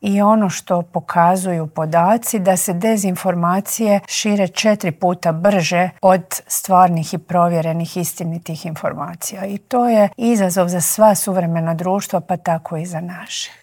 Treba 'ohladiti glave' i spustiti tenzije, zaključila je na kraju intervjua ministrica Obuljen Koržinek.